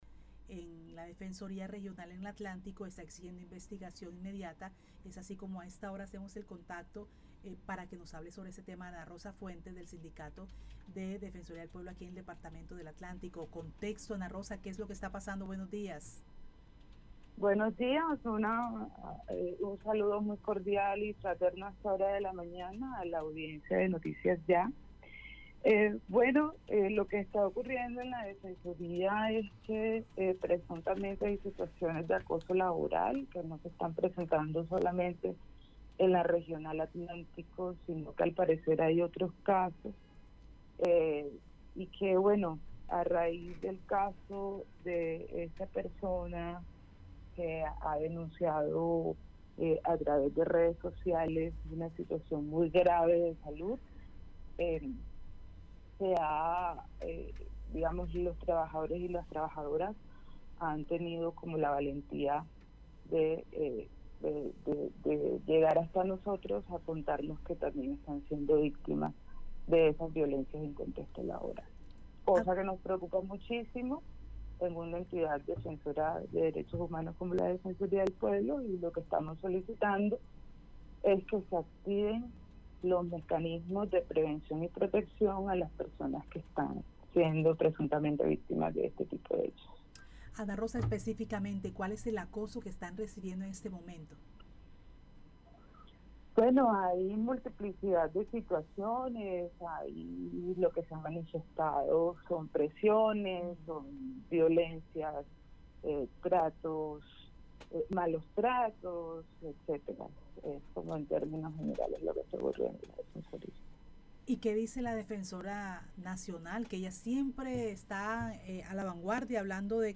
En diálogo con Noticias Ya